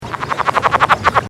Release Calls
Sounds  This is a very short recording of the release calls of an adult Cascades Frog recorded during daylight at a mountain lake in Washington state.
Birds can be heard in the background.